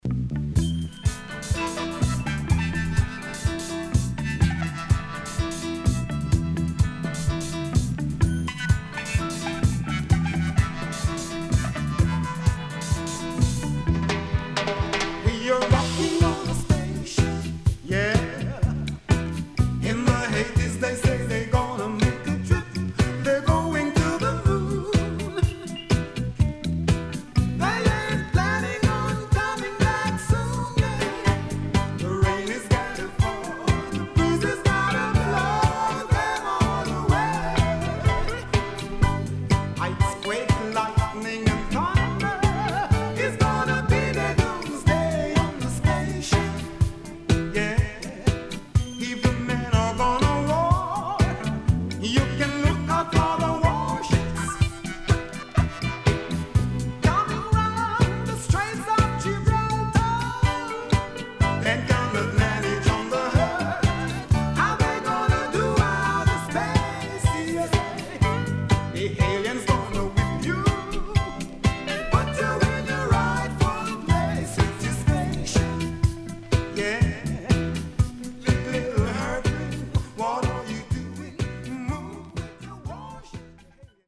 SIDE B:軽いヒスノイズ入りますが良好です。